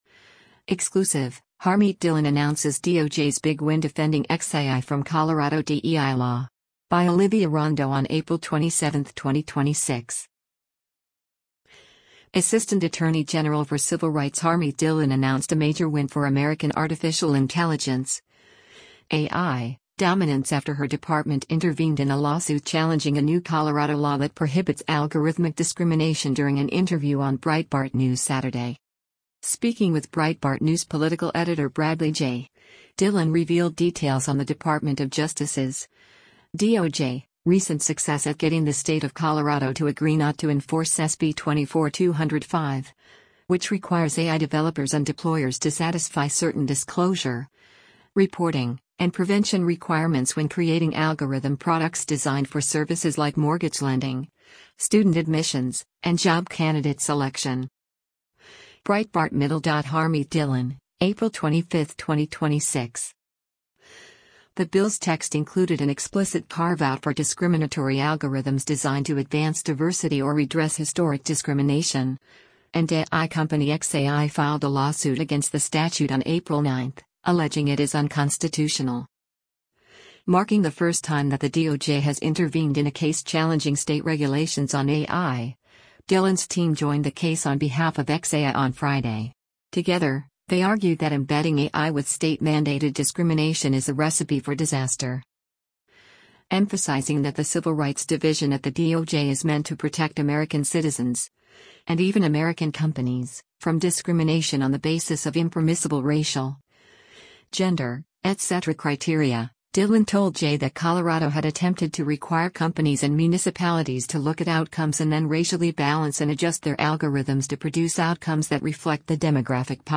Assistant Attorney General for Civil Rights Harmeet Dhillon announced a major win for American artificial intelligence (AI) dominance after her department intervened in a lawsuit challenging a new Colorado law that prohibits “algorithmic discrimination” during an interview on Breitbart News Saturday.